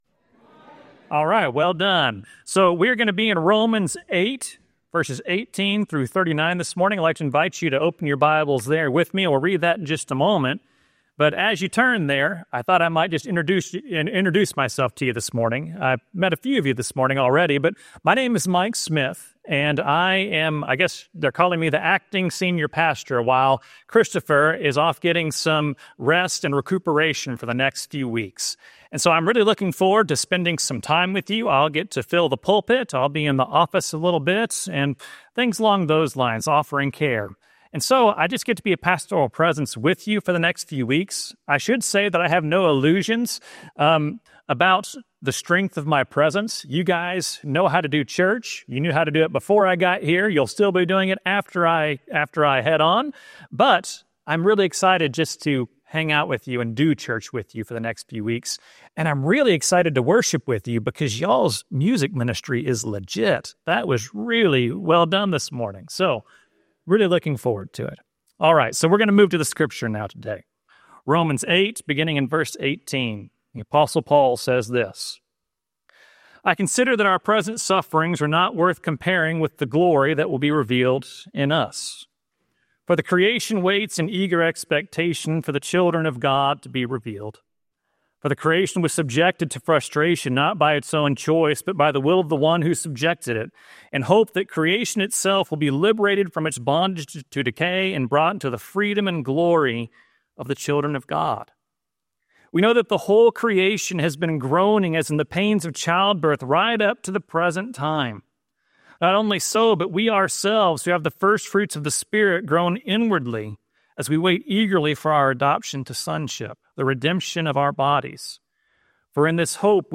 Romans 8:18-39 Service Type: Traditional Service Let the Spirit meet you in struggle.